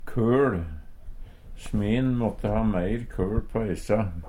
køL - Numedalsmål (en-US)
DIALEKTORD PÅ NORMERT NORSK køL kol Eintal ubunde Eintal bunde Fleirtal ubunde Fleirtal bunde eit køL køLe Eksempel på bruk Sme `n måtte ha meir køL på essa.